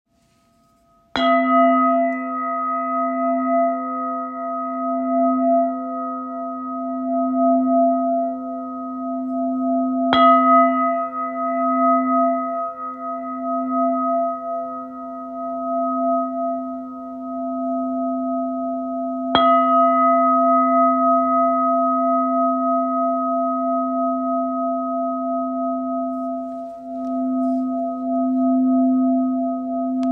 Zpívající křišťálový kalich
432 Hz
Křišťálový kalich - tón C